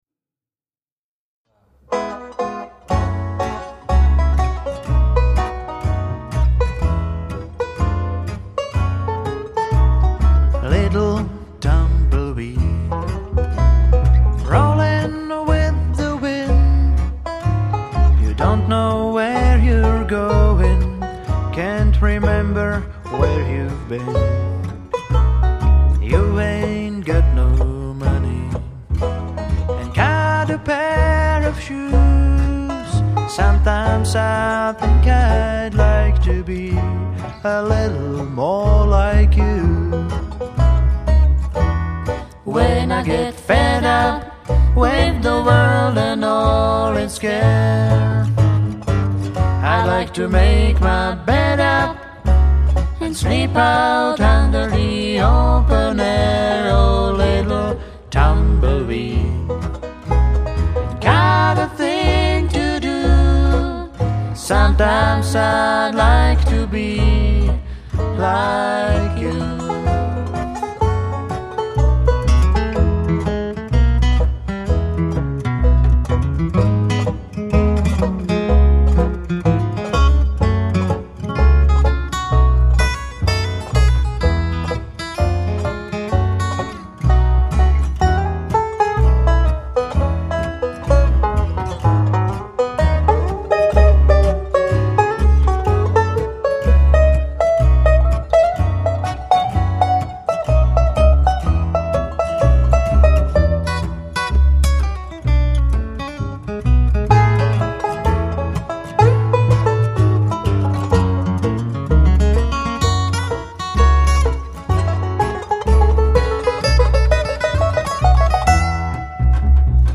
živá demo nahrávka
banjo
kytara
kontrabas, zpěv